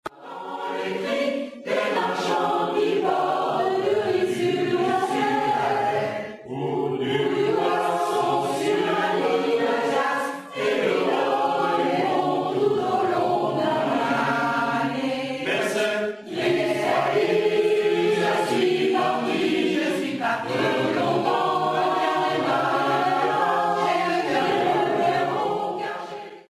chorale A PORTEE DE VOIX
Nous avions alors interprété 5 chants seuls: